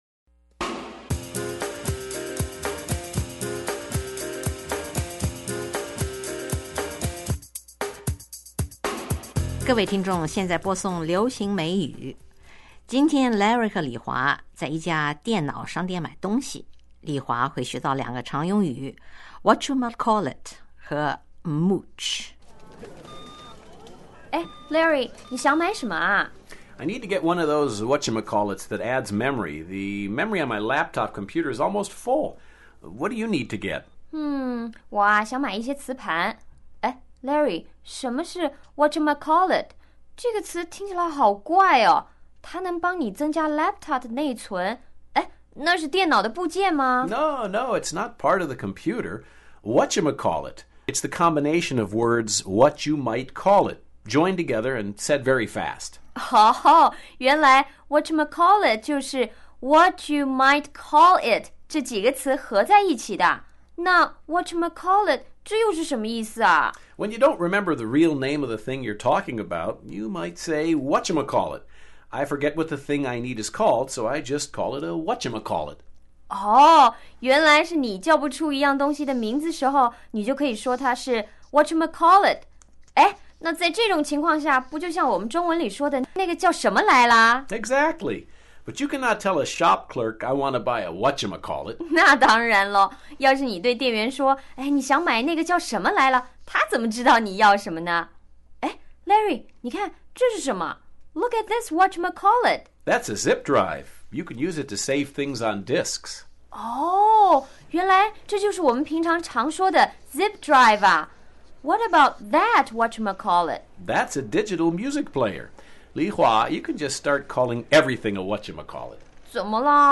(Computer Store SFX)